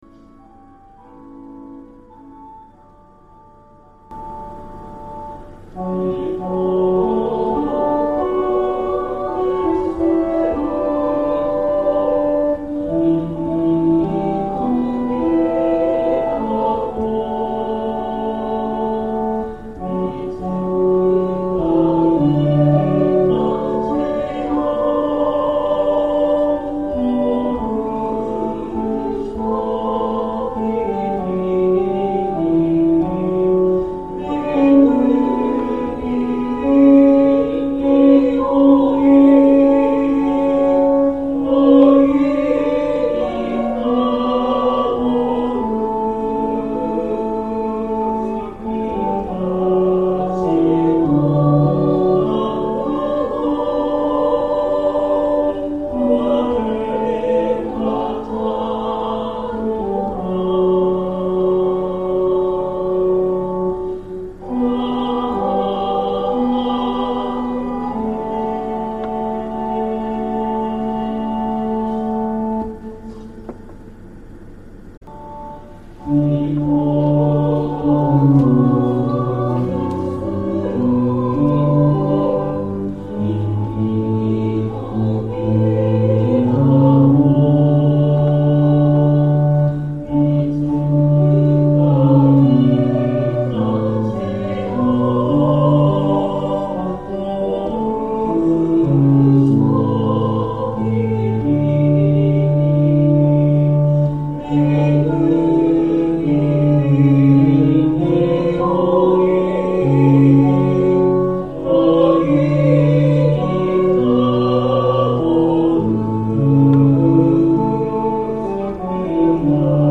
Sound♪ 2節のソプラノ・オブリガート(MIDI) - オーボエ音で強調してあります